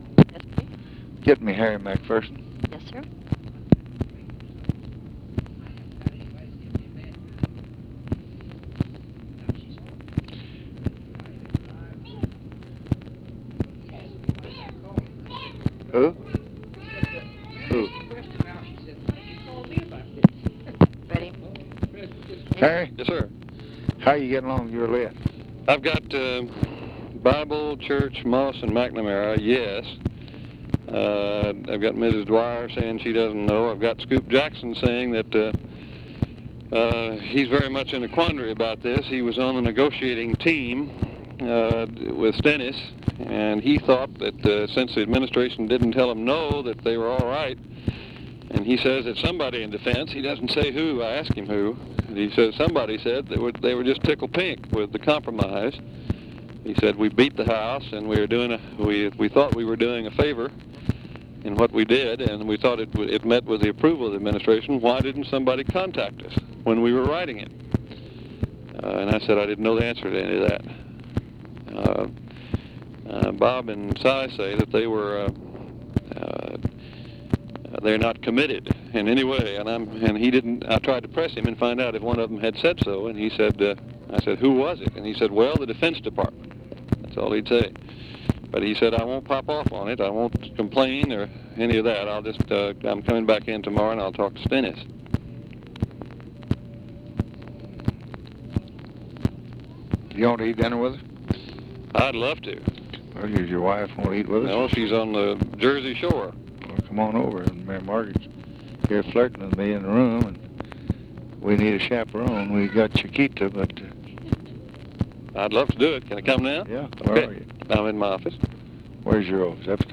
Conversation with HARRY MCPHERSON and OFFICE CONVERSATION, August 21, 1965
Secret White House Tapes